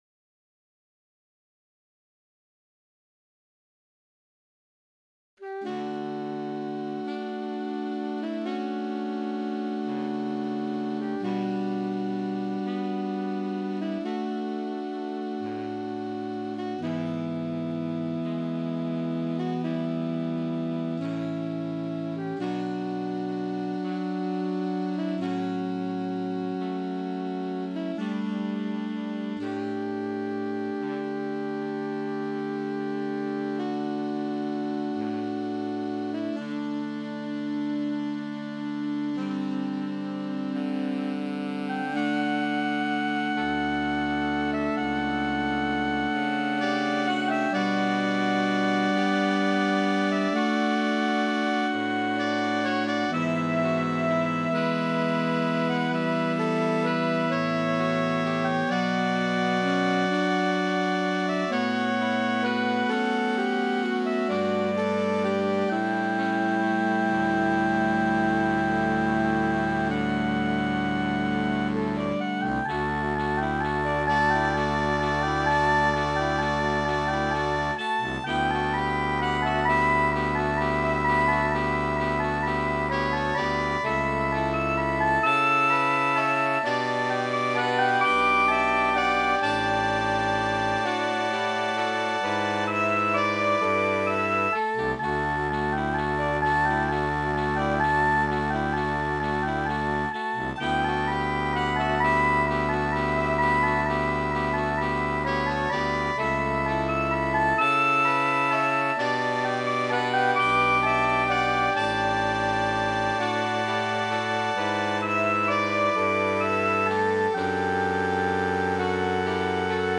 CocomazziA-CantoAgreste-Sxens-MIDI.mp3